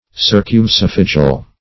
Meaning of circumesophageal. circumesophageal synonyms, pronunciation, spelling and more from Free Dictionary.
Circumesophageal \Cir`cum*e`so*phag"e*al\